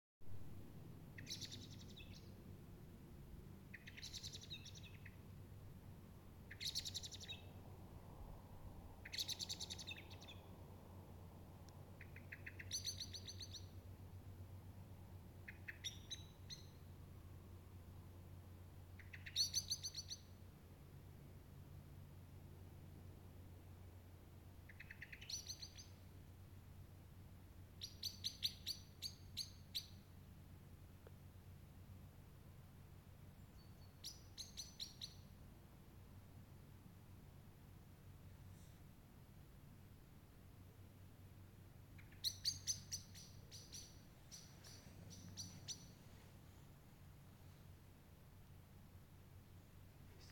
черный дрозд, Turdus merula
Ziņotāja saglabāts vietas nosaukumsKrimuldas parks
СтатусСлышен голос, крики